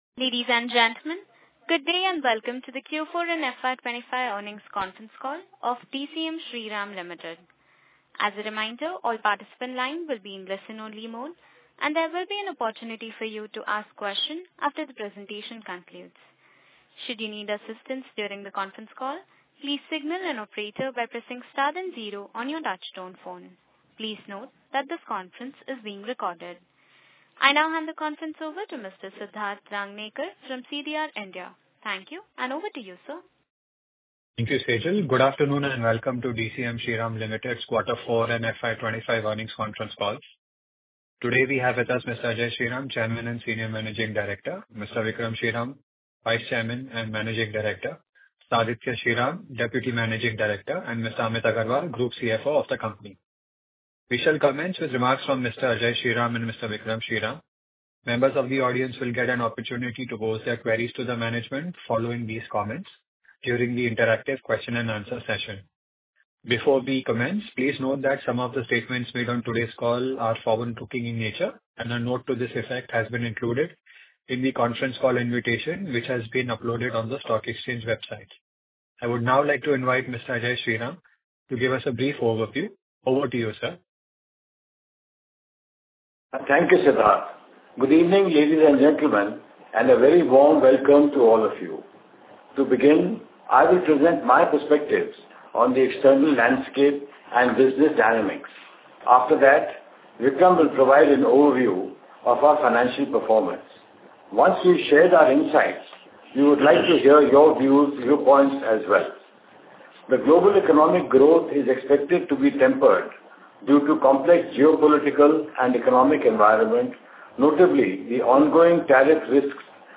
DCM Shriram Limited - Q4 FY25 Earnings Call - Audio Recording.mp3